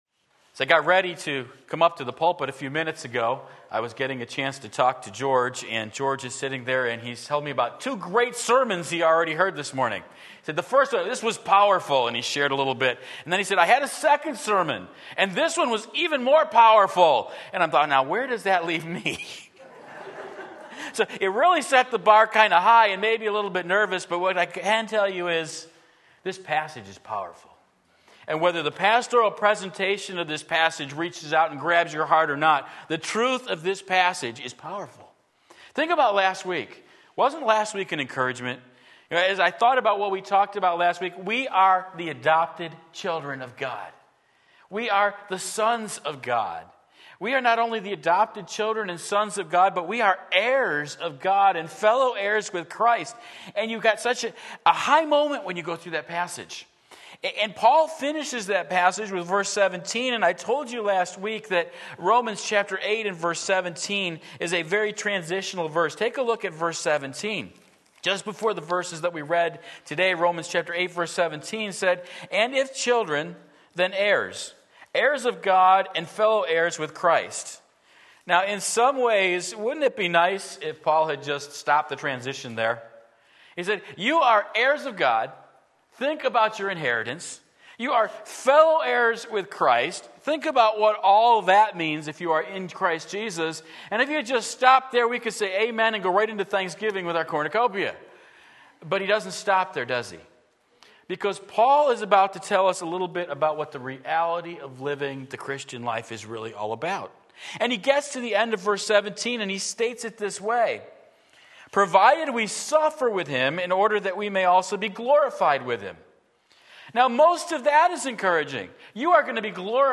Sermon Link
Hope in the Midst of Suffering Romans 8:18-23 Sunday Morning Service